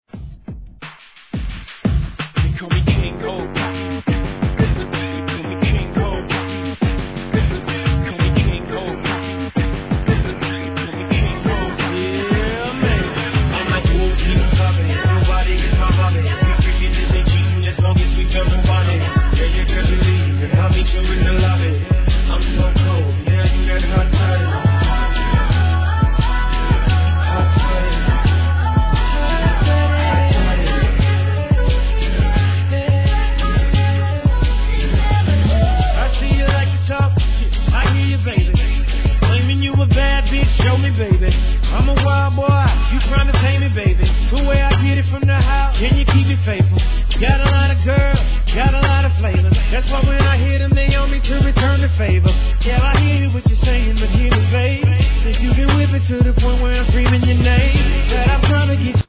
HIP HOP/R&B
BPM87